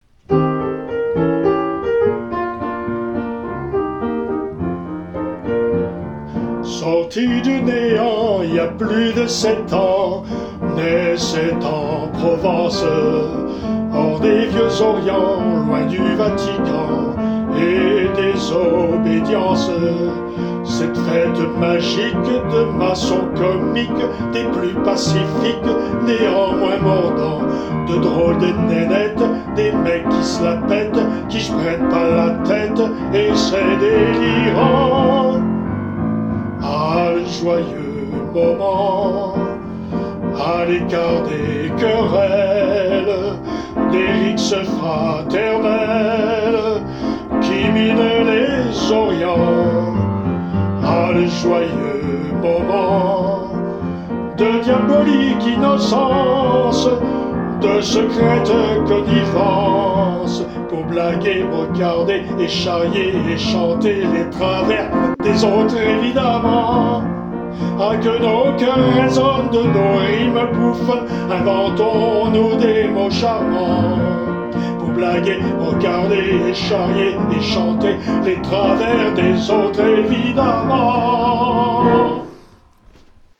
Version solo